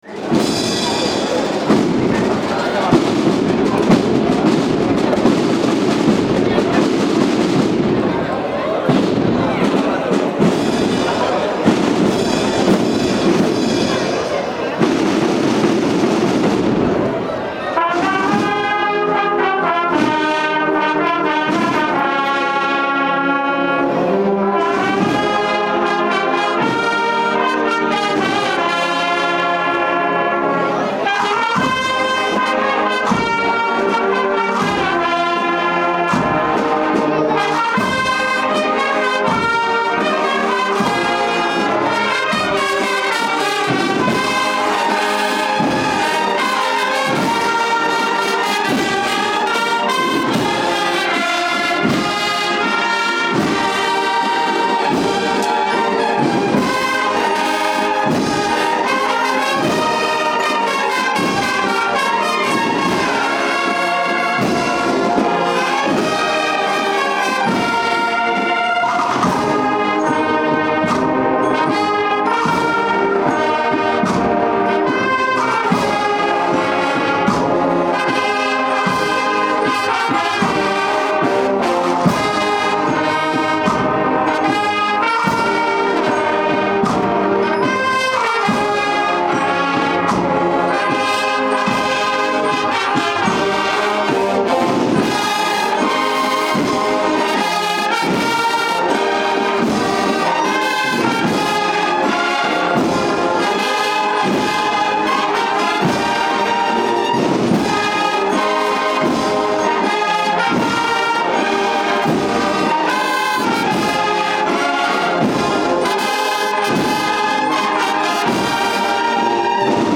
Certamen de Bandas de Cornetas y Tambores y Agrupaciones Musicales 2014
Semana Santa de Totana
Audio: Actuación conjunta  de la  Agrupación Musical Hermandad de Jesús y la Samaritana de Totana y la Agrupación Musical del Paso Encarnado de Lorca.
bandas nazarenas